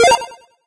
8bit_reload_01.ogg